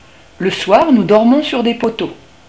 Clique sur la note pour écouter mon chant.